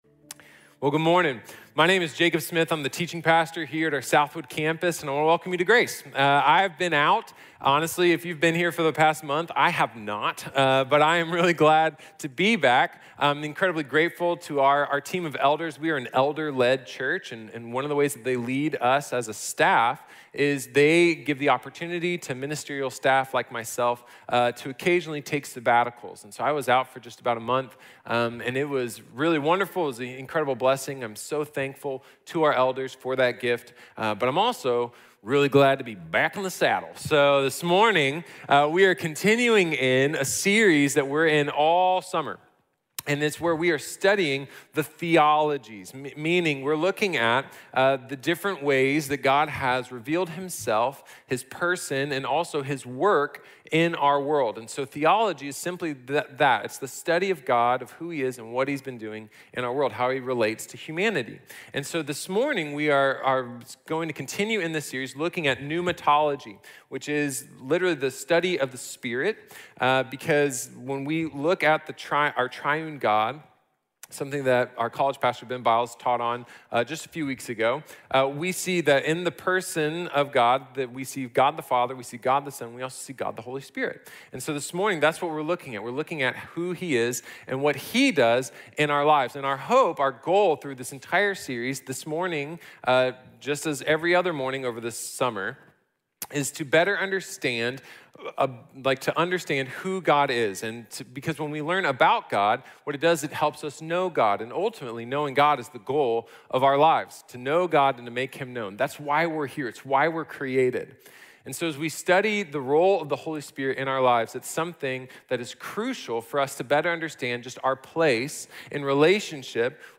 Neumatología | Sermón | Iglesia Bíblica de la Gracia